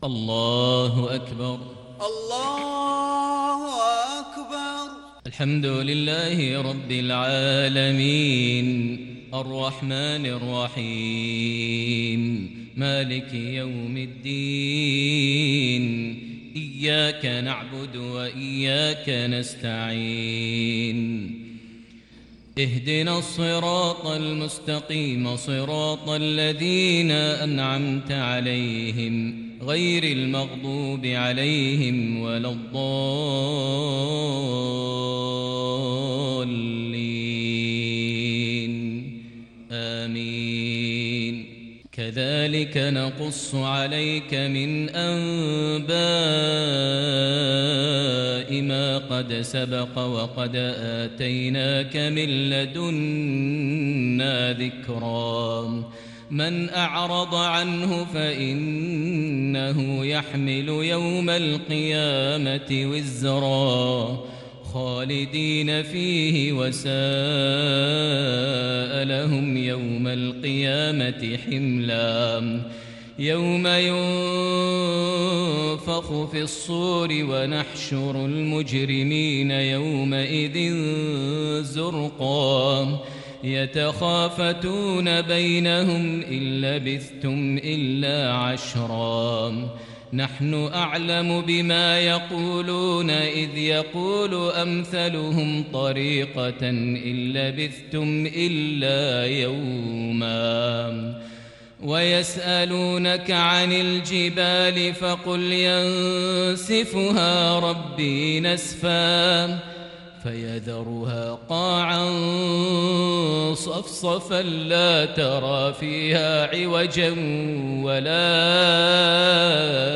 صلاة المغرب للشيخ ماهر المعيقلي 25 صفر 1442 هـ
تِلَاوَات الْحَرَمَيْن .